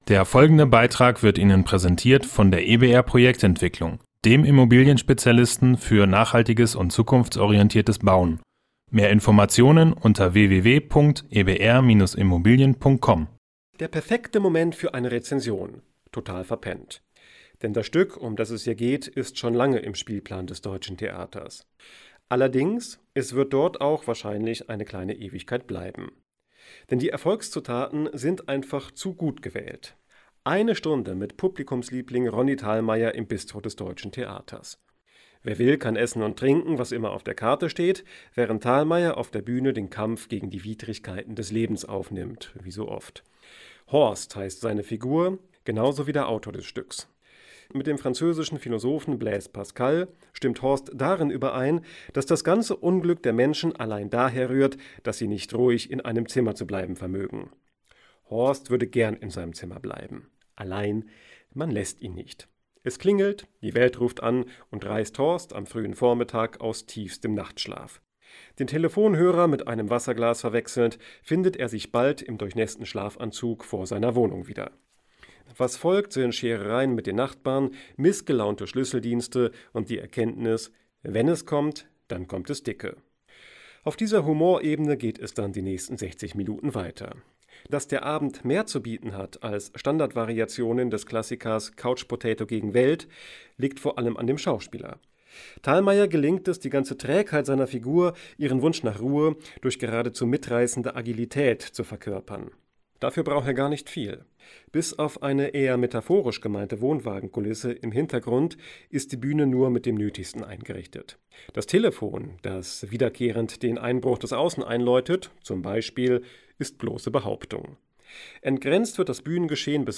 Beiträge > "Der perfekte Moment - total verpennt" im Deutschen Theater - Rezension - StadtRadio Göttingen